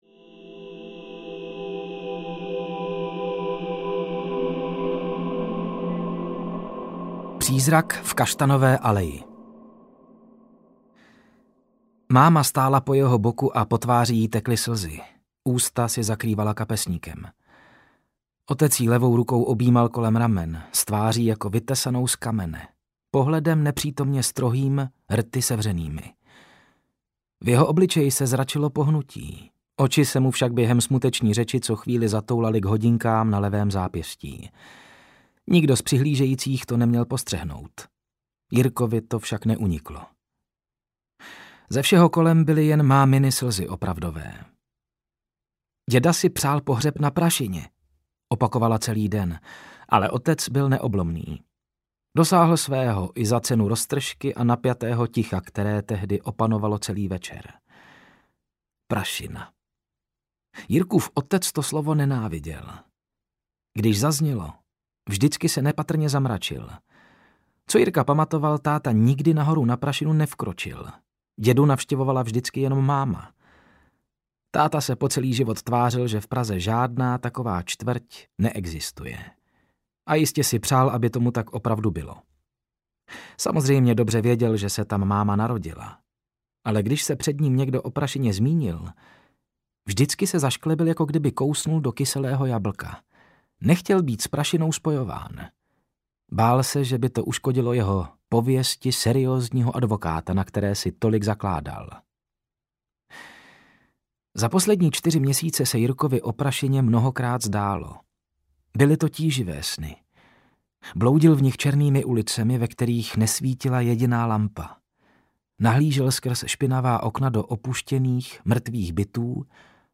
Prašina - Černý merkurit audiokniha
Ukázka z knihy